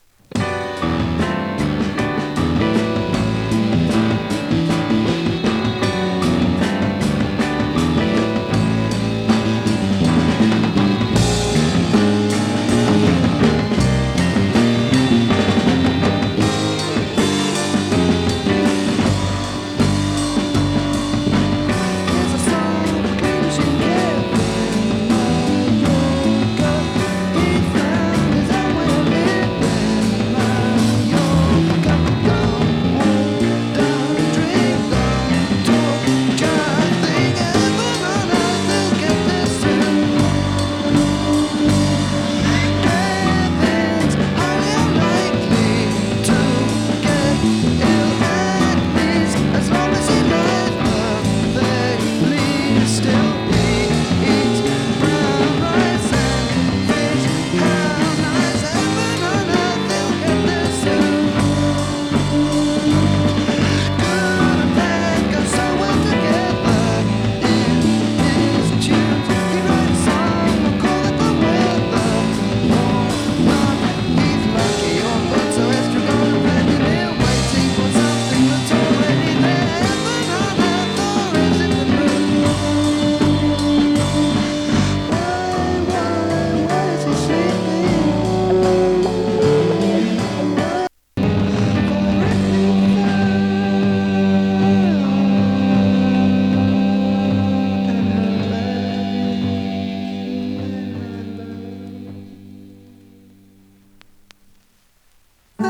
音の薄い部分で軽いサー・ノイズ。時折軽いパチ・ノイズ。